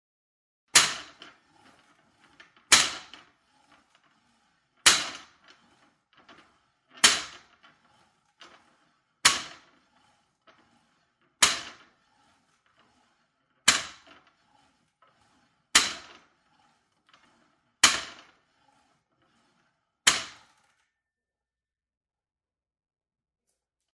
Звуки тренажерного зала
Человек тренируется на тренажере дома